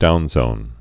(dounzōn)